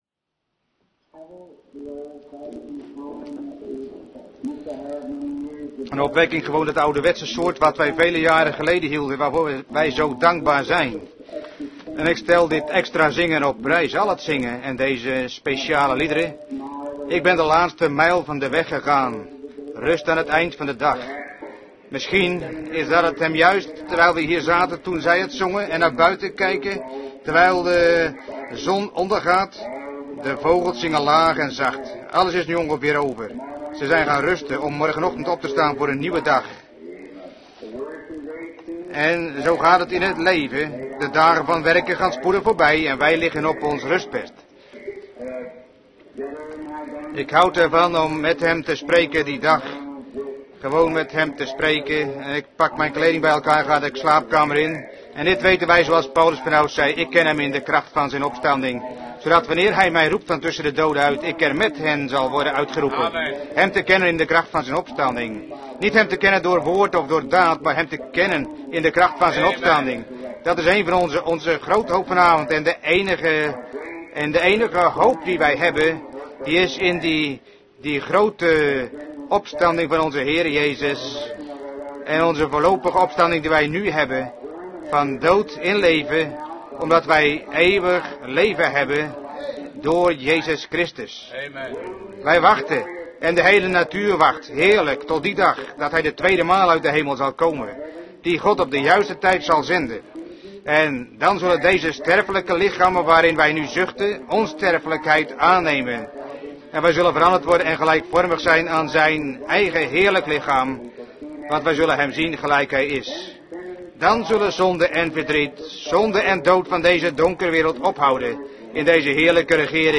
De vertaalde prediking "The seal of God" door William Marrion Branham gehouden in Branham Tabernacle, Jeffersonville, Indiana, USA, op vrijdag 14 mei 1954